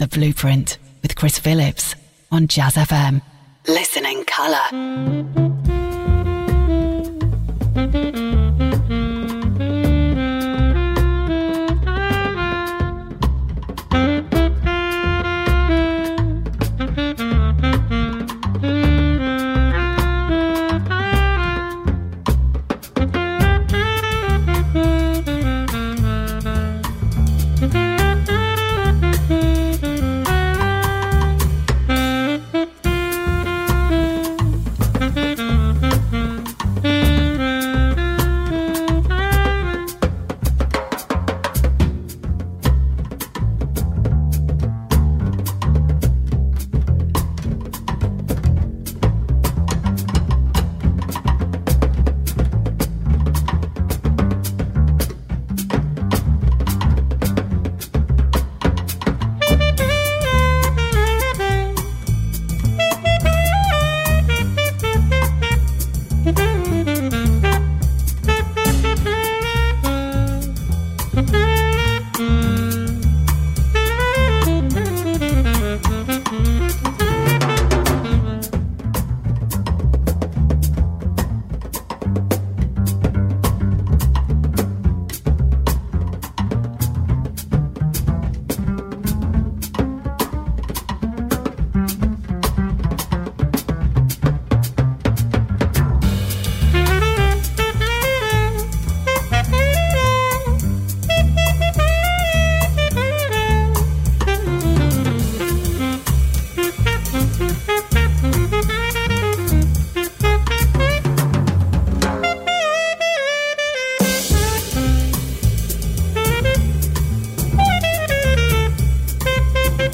Interviews and Live Sessions